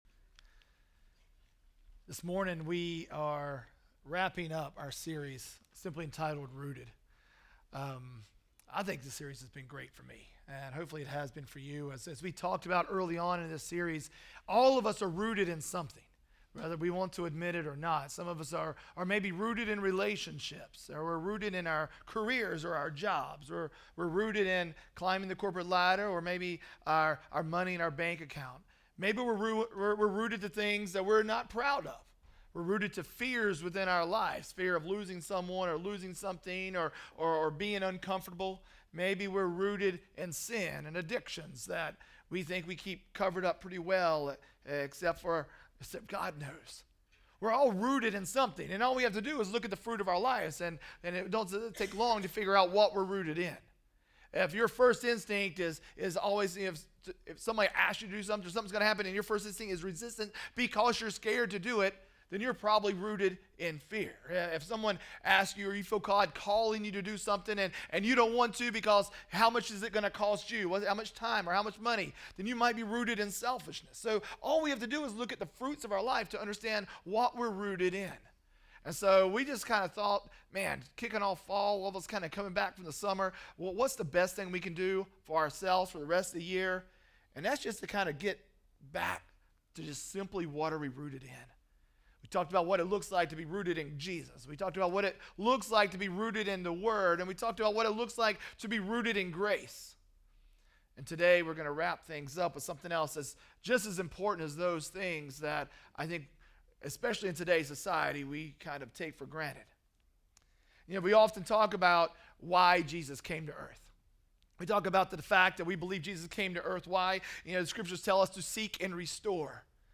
Project:Re3 RE3 Sermon Audio